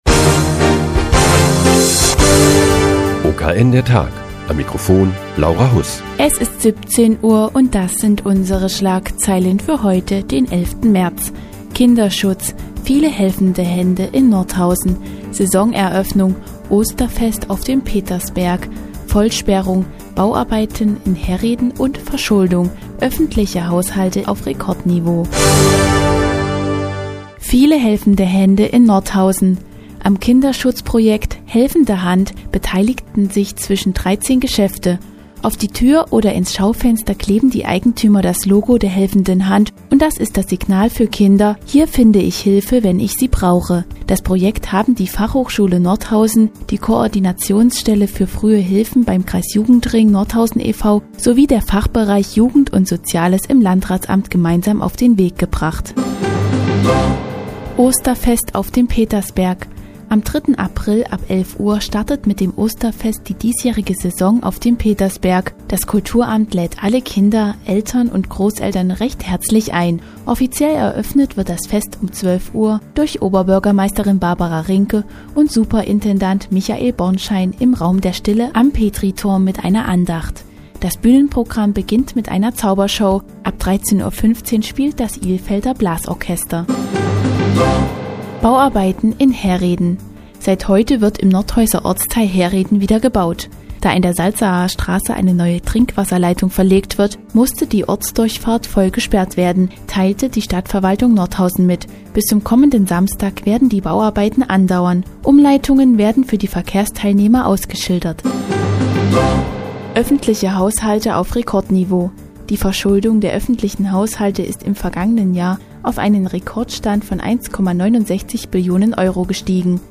Die tägliche Nachrichtensendung des OKN ist nun auch in der nnz zu hören. Heute geht es um das Kinderschutz-Projekt "Helfende Hand" und das Osterfest auf dem Petersberg.